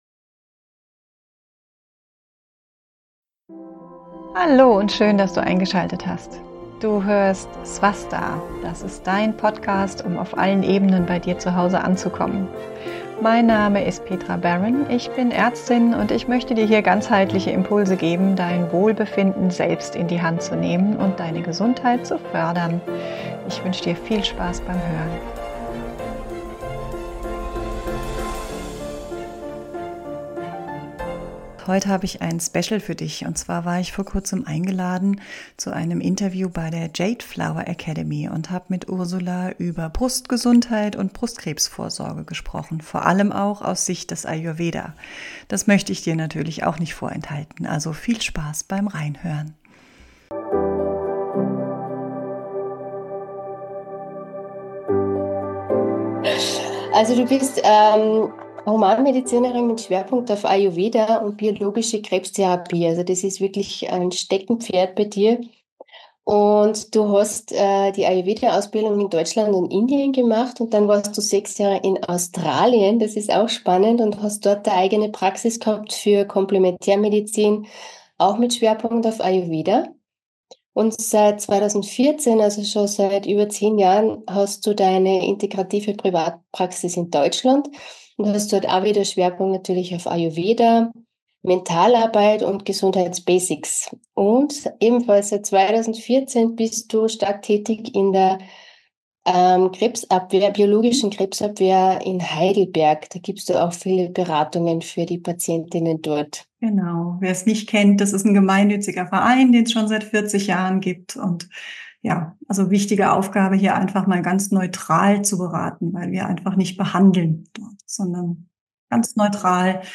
Beschreibung vor 1 Jahr Mein Gastinterview bei den Newmoon Talks der Jadeflower Academy Brustkrebs verstehen – ganzheitlich vorbeugen mit Ayurveda und Schulmedizin In meinem neuesten Podcast-Interview bei Jadeflower spreche ich über ein Thema, das mir besonders am Herzen liegt: Brustkrebs.